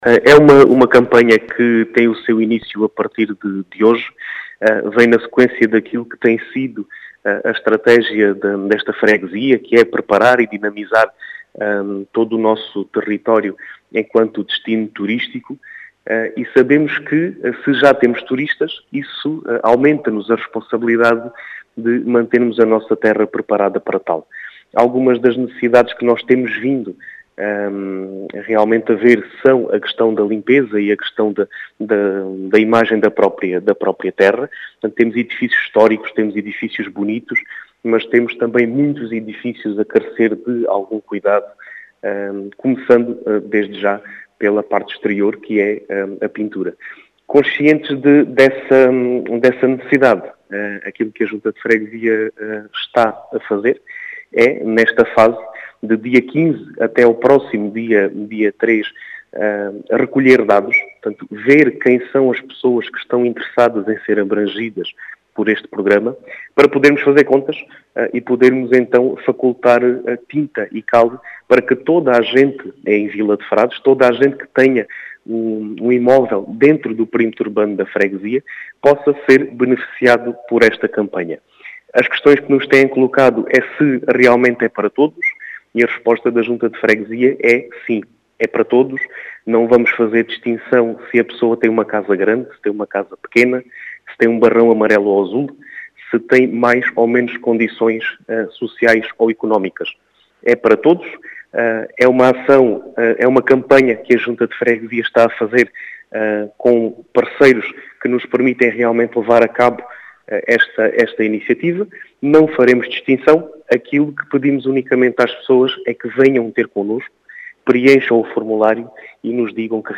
As explicações são do presidente da junta de freguesia de Vila de frades, Diogo Conqueiro, que quer melhorar a imagem da freguesia, enquanto destino turístico, dizendo que a campanha “é para todos”.